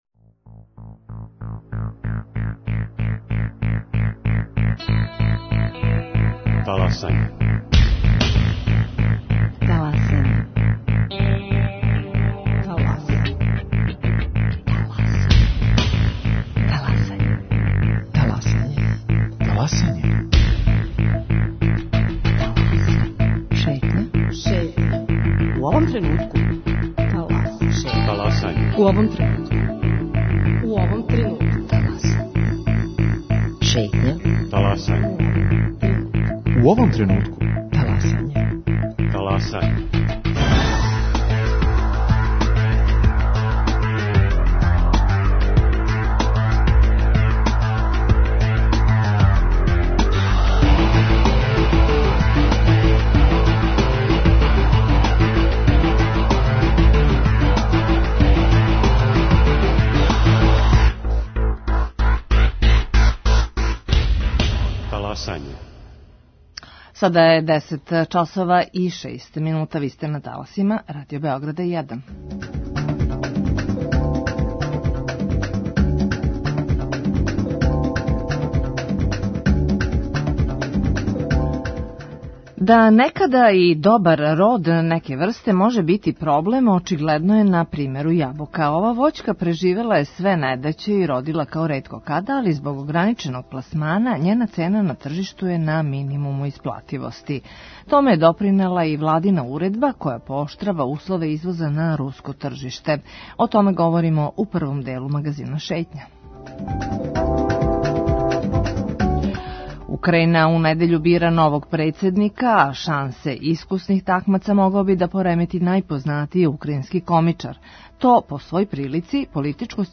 Чућемо шта кажу произвођачи, али и струка.